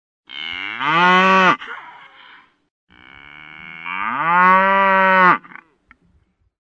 » Корова мычит Размер: 55 кб